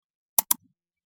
Mouse Click 02
Mouse_click_02.mp3